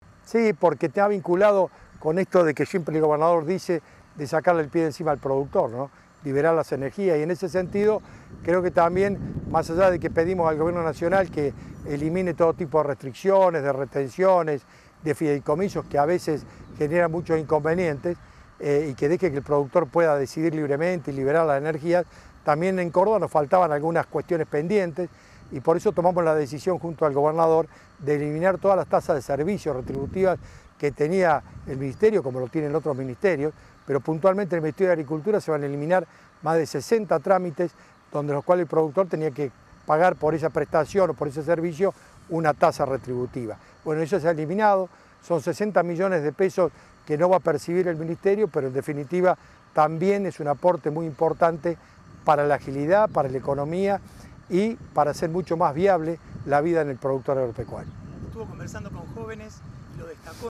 Lo hizo en su discurso durante el lanzamiento de la Cosecha de Trigo, en Leones.
A continuación compartimos las principales declaraciones del Ministro